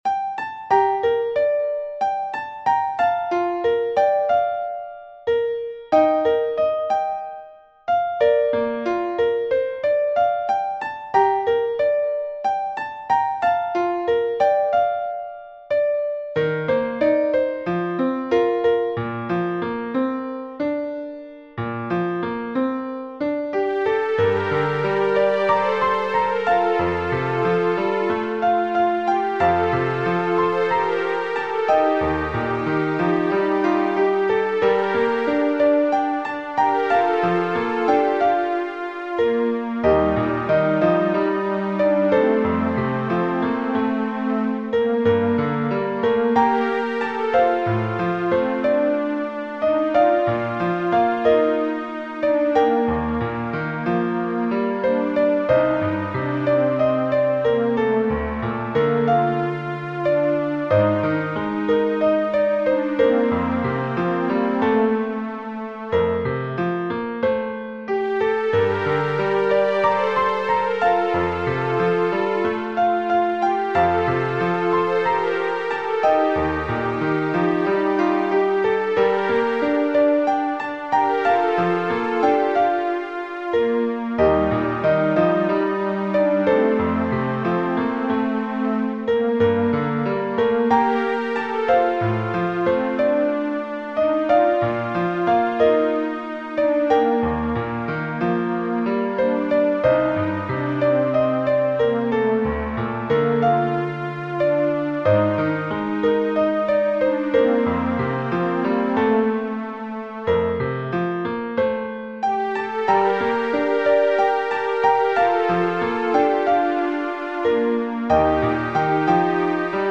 The Sacrifice - SA Duet
Voicing/Instrumentation: SA , Duet